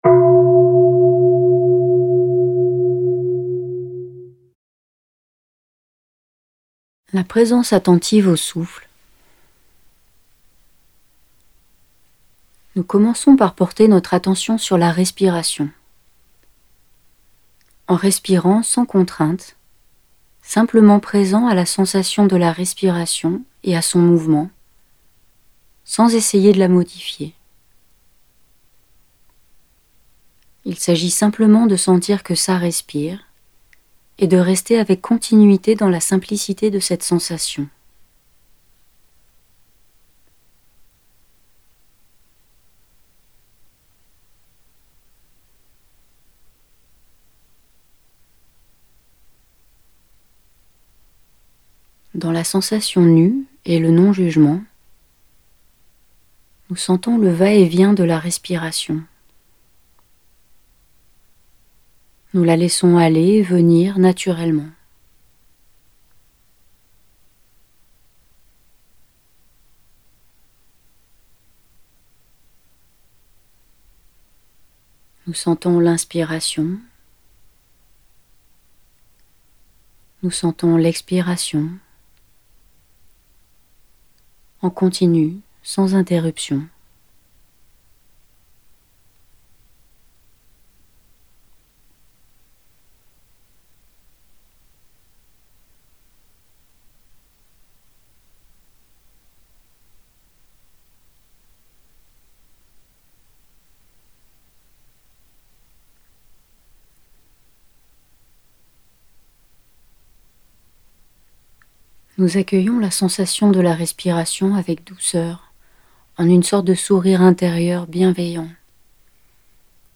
Audio femme
3.ETAPE-2-AUDIO-4-P12-FEMME.mp3